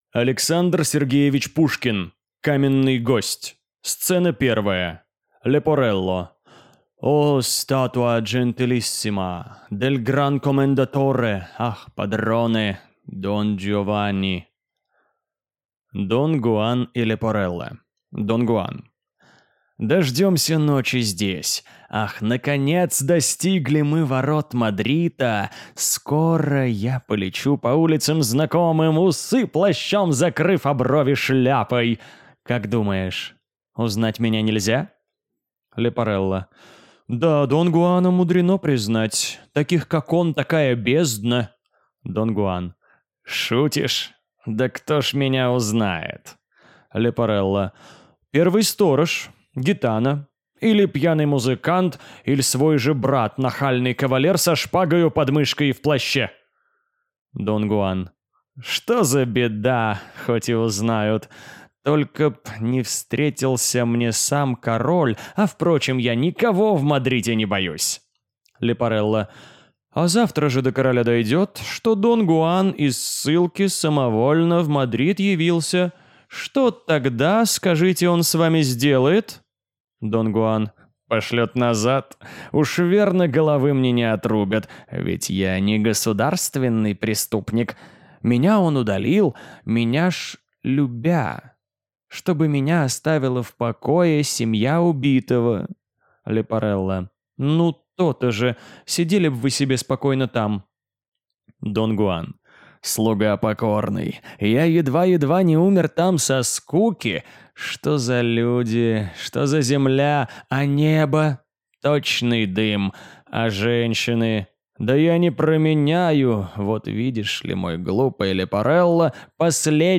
Аудиокнига Каменный гость | Библиотека аудиокниг
Прослушать и бесплатно скачать фрагмент аудиокниги